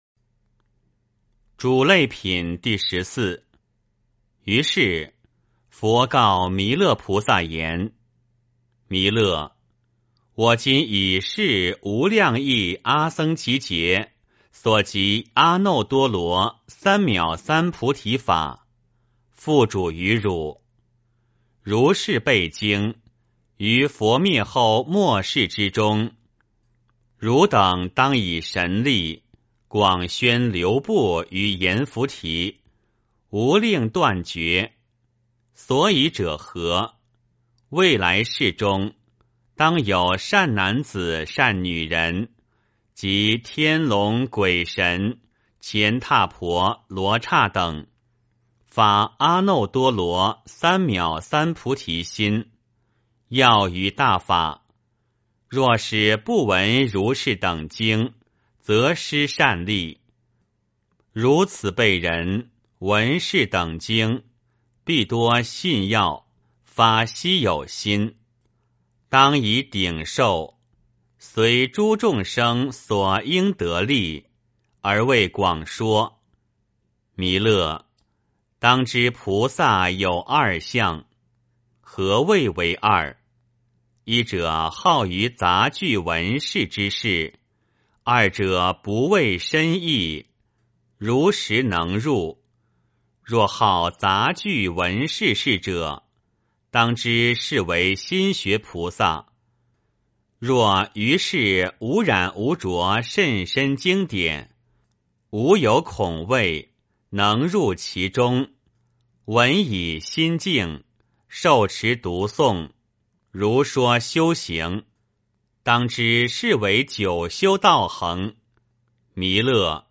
维摩诘经-嘱累品第十四 - 诵经 - 云佛论坛